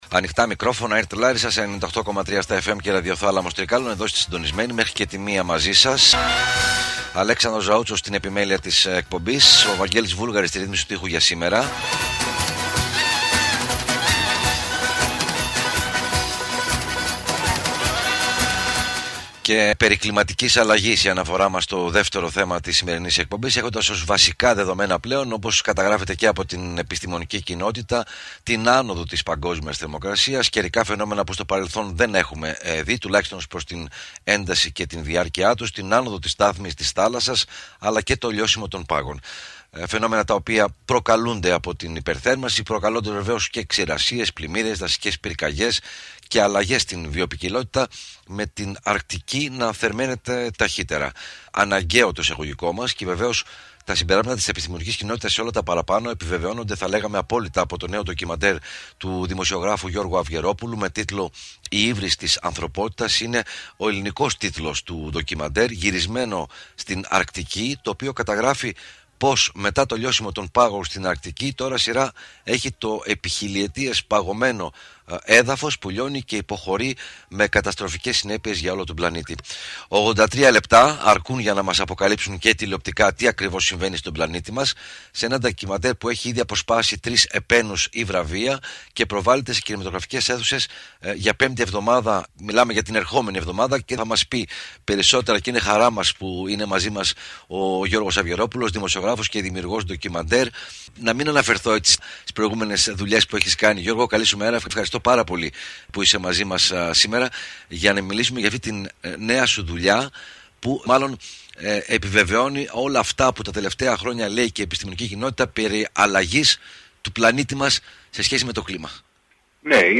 Ο δημοσιογράφος Γιώργος Αυγερόπουλος μιλά για τo νέο του ντοκιμαντέρ “Η Ύβρις της Ανθρωπότητας” (MANKIND’S FOLLY) που πραγματεύεται την κλιματική αλλαγή του πλανήτη, με επίκεντρο το λιώσιμο του permafrost στην Αλάσκα και τη Σιβηρία.
από τον Ραδιοθάλαμο Τρικάλων της ΕΡΤ Λάρισας (98,3 στα fm).